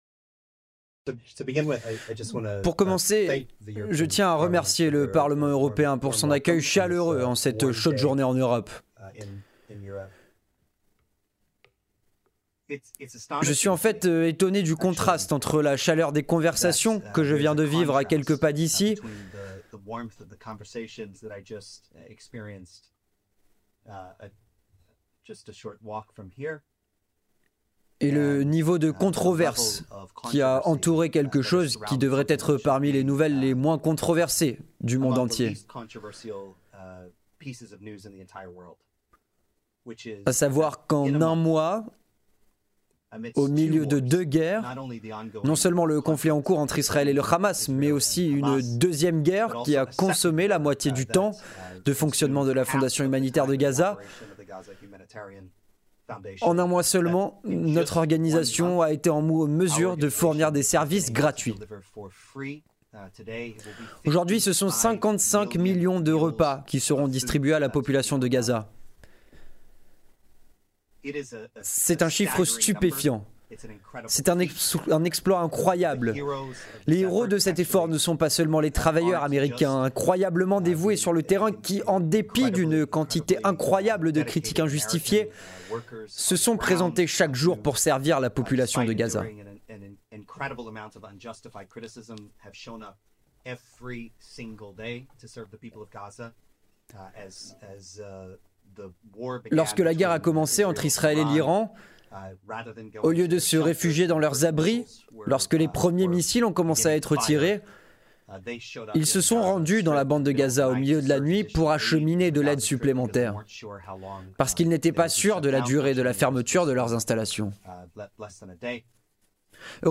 La Fondation humanitaire de Gaza en conférence de presse à Bruxelles (04/07/25)
Avec le révérend Johnnie Moore, le président exécutif de la Fondation humanitaire de Gaza.